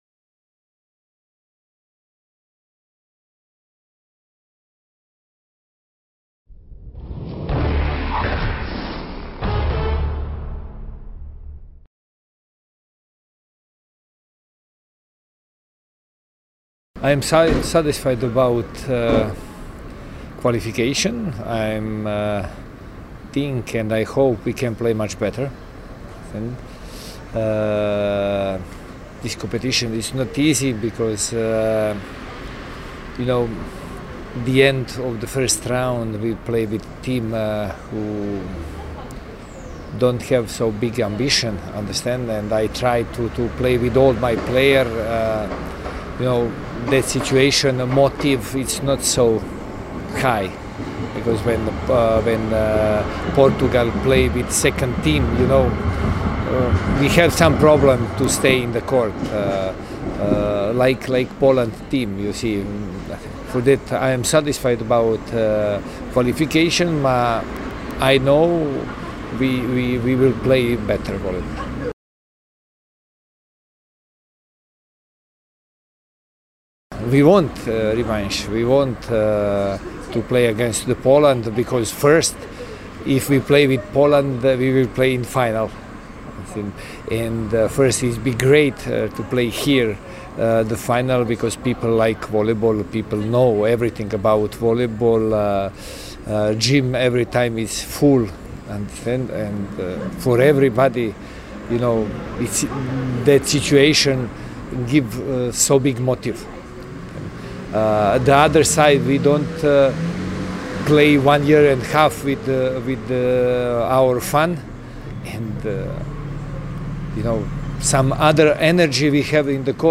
PGE Skra realizuje jednak program, który wcześniej ustaliliśmy, więc mam nadzieję, że będziemy dobrze przygotowani do ligi – mówi Slobodan Kovac, trener bełchatowian.